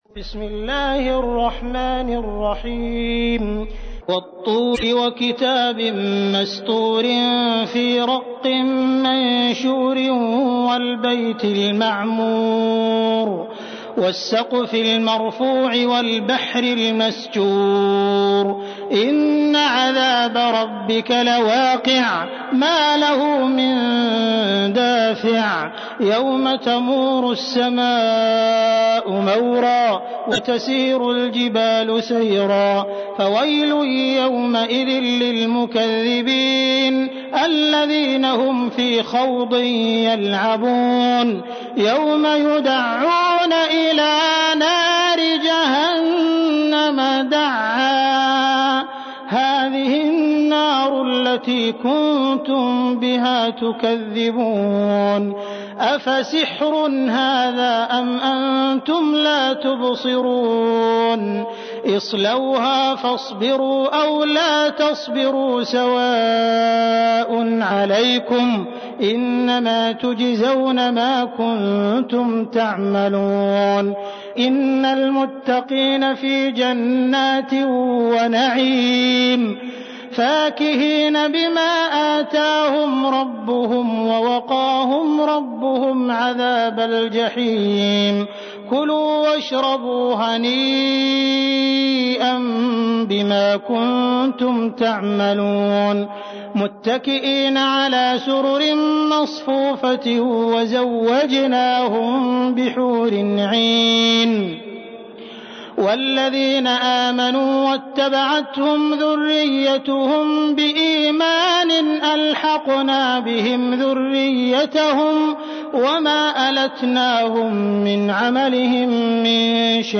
تحميل : 52. سورة الطور / القارئ عبد الرحمن السديس / القرآن الكريم / موقع يا حسين